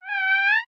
wawa_statement.ogg